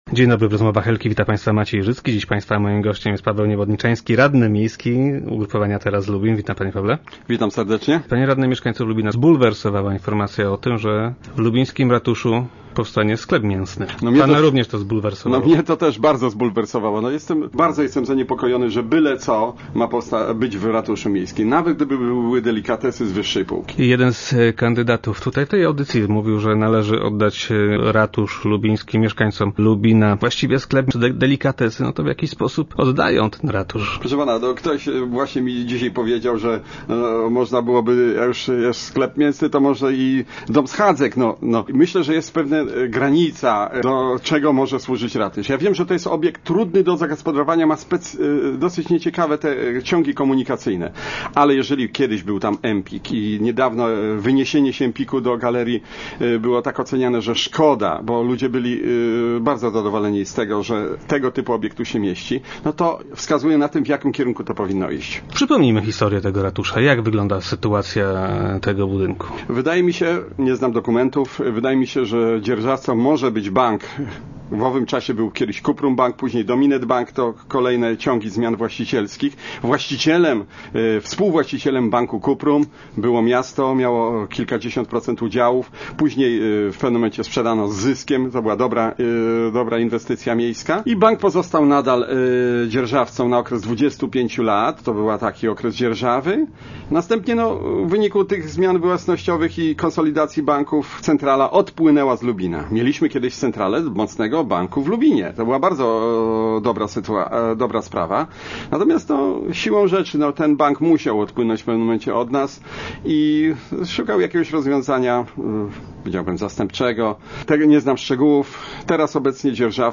Radny Teraz Lubin był gościem poniedziałkowych Rozmów Elki.